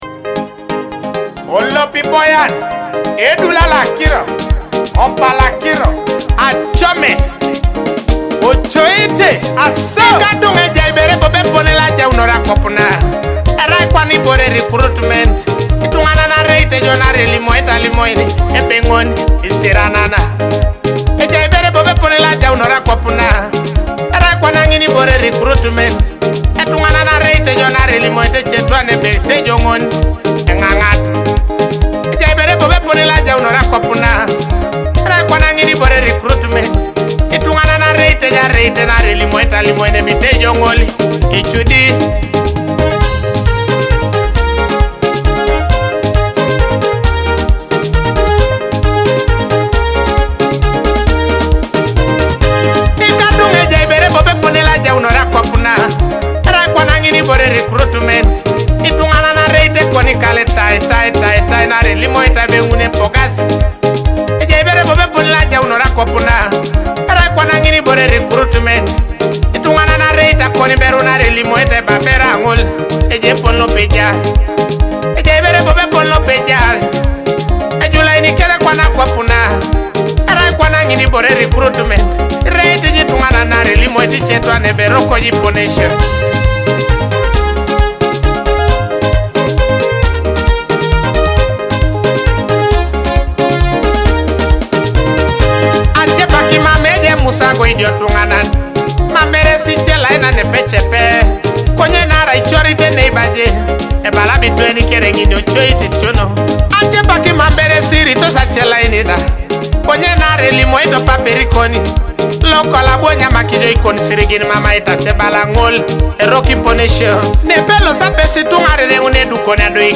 Explore Teso melodies